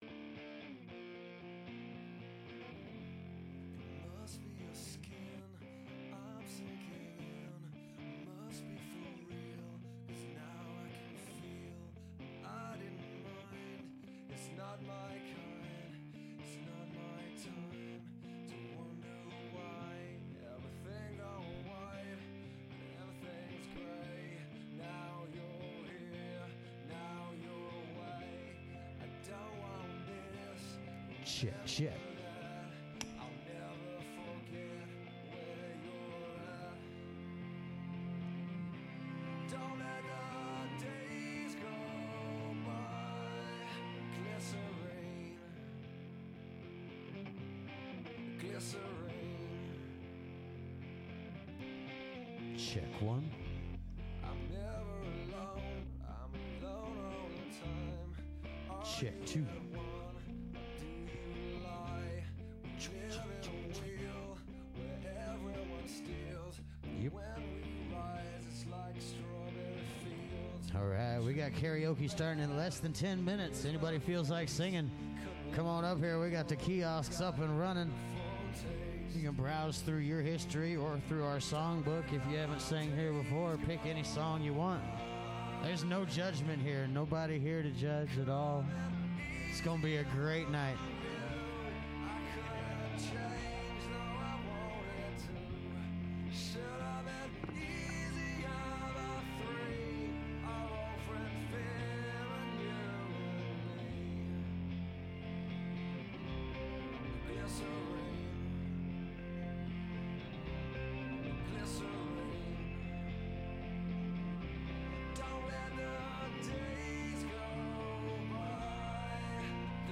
Mile High Karaoke Live Wednesday through Saturday 9-1 Broadways Shot Spot